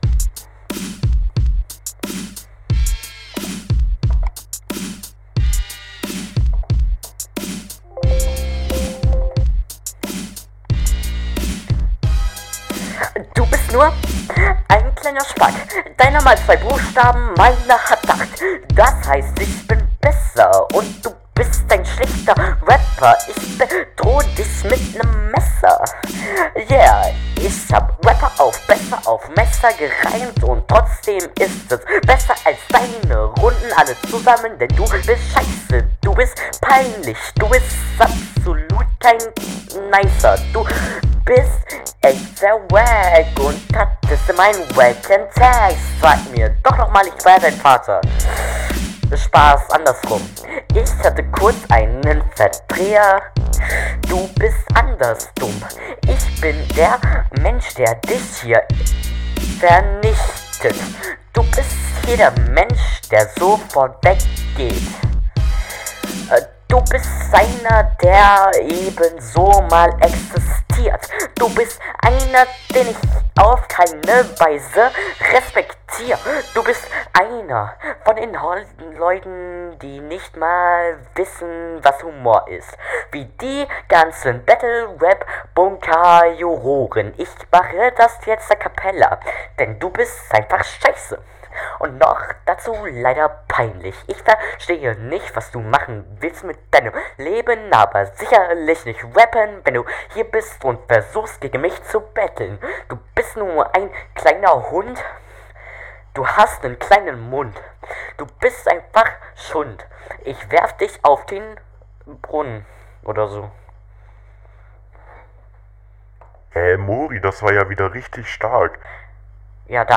Freestyle und kein Beat mehr. Irgendwie sehr anstrengend. Punches 0. Lieber vollen Beat ausnutzen. !
Deine Audioqualität ist leider wieder sehr schlecht.